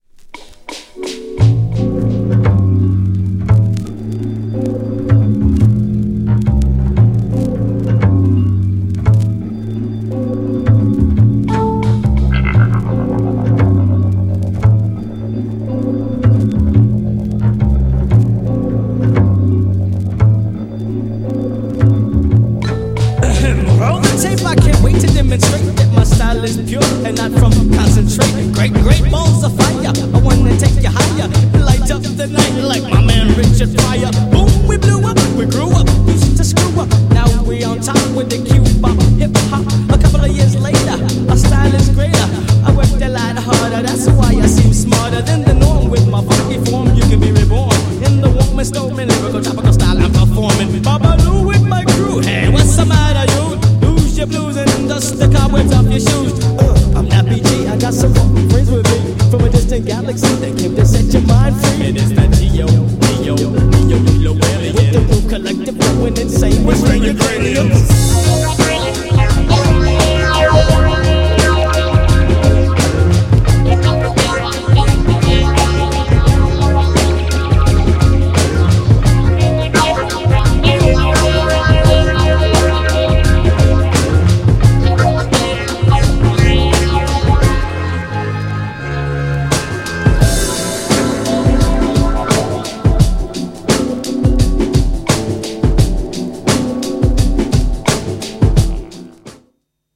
NYで結成された90年代のJAZZ FUNKグループ。
GENRE R&B
BPM 96〜100BPM